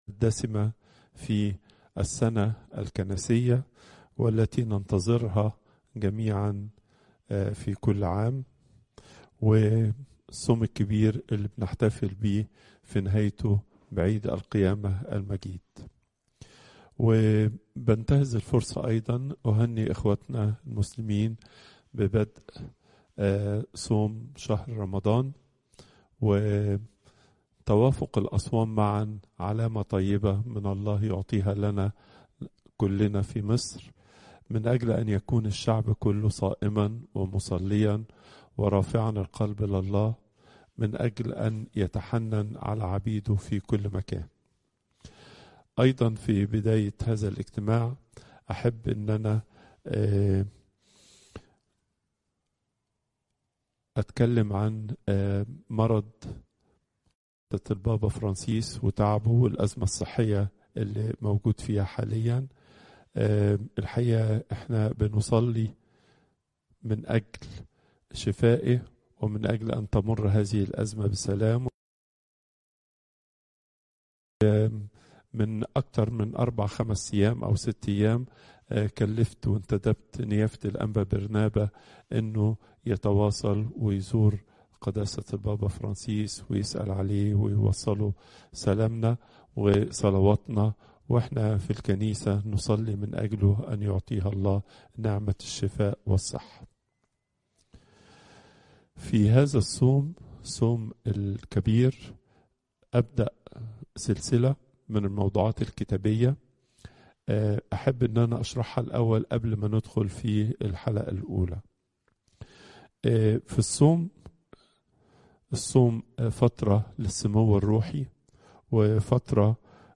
Popup Player Download Audio Pope Twadros II Wednesday, 26 February 2025 36:42 Pope Tawdroes II Weekly Lecture Hits: 260